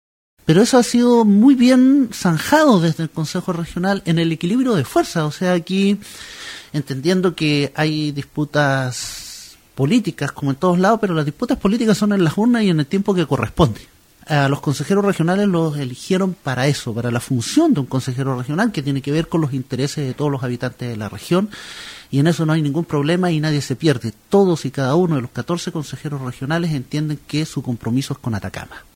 En la conversación con Nostálgica, Javier Castillo destacó la forma y la manera en que ha trabajado el CORE de Atacama en el último tiempo, donde se ha privilegiado el trabajo por la región, más allá de los intereses de los colores políticos de los 14 integrantes de este organismo tan importante para el desarrollo de la región: